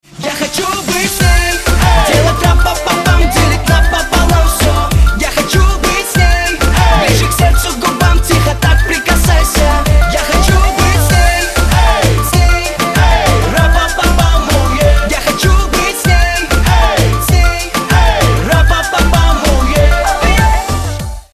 Хип-хоп
веселые
заводные
RnB